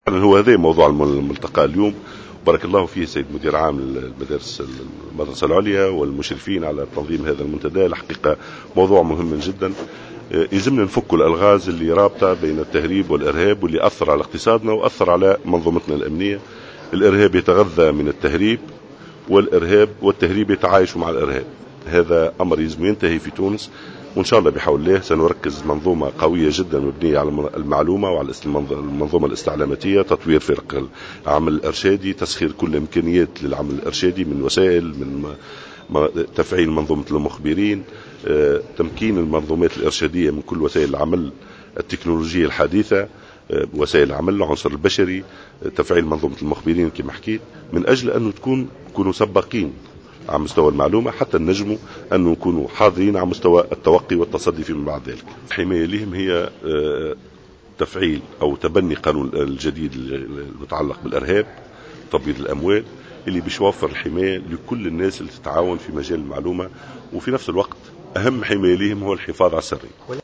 وأضاف على هامش يوم دراسي حول قوات الأمن الداخلي ورهانات مجابهة الإرهاب والتهريب أن الوزارة تعمل على تسخير الإمكانيات الضرورية للعمل الإرشادي وتفعيل منظومة المخبرين.